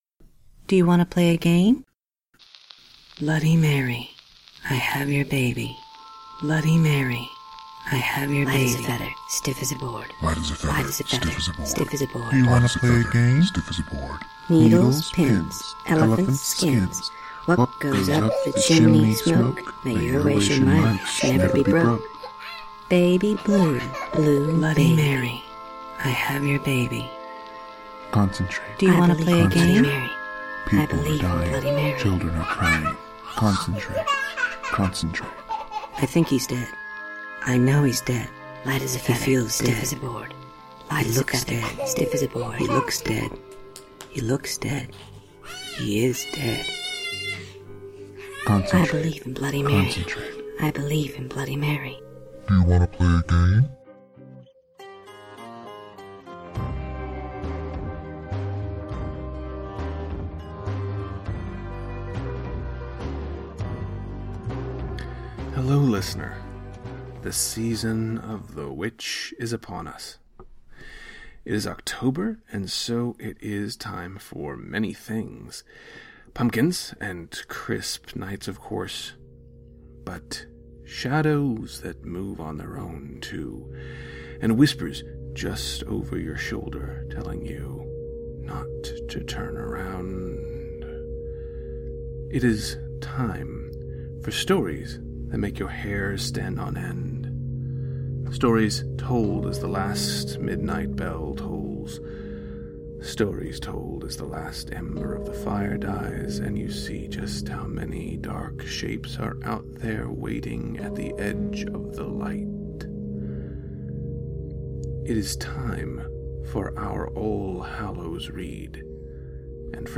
Sound effects are sourced from Creative Commons licensed recordings at Sound Bible .